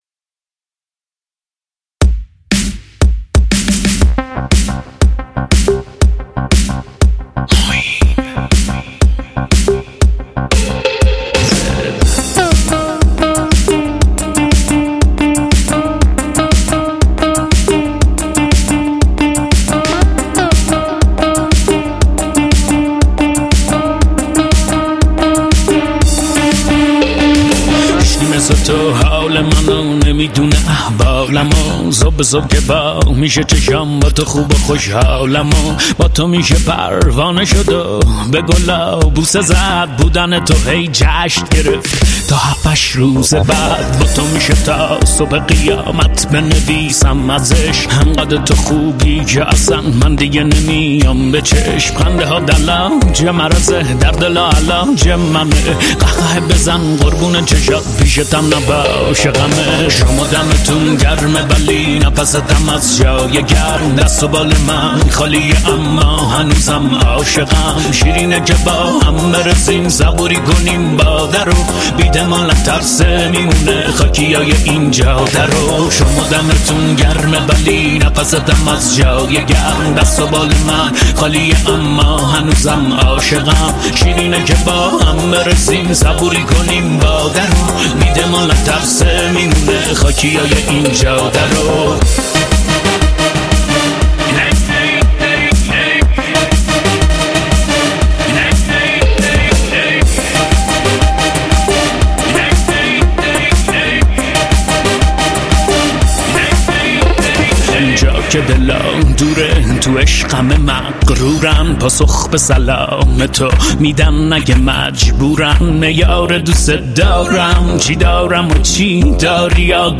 ریمیکس تند بیس دار